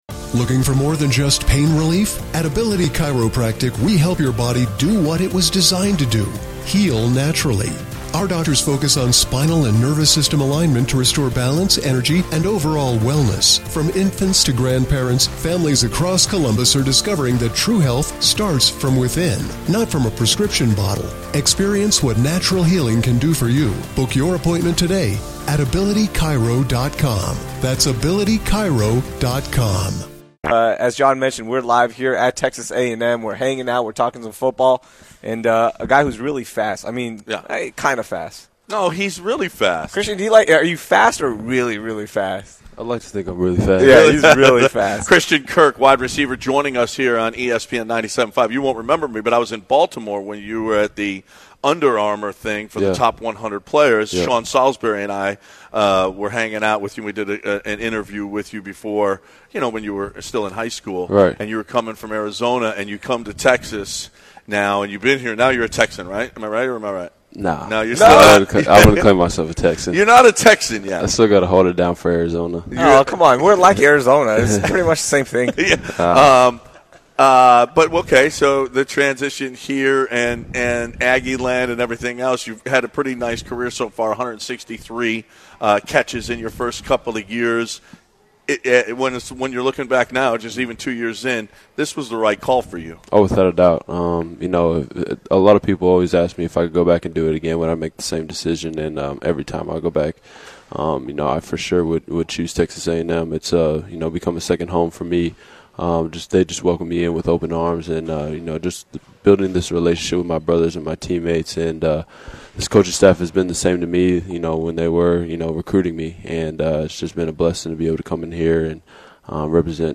Christian Kirk Interview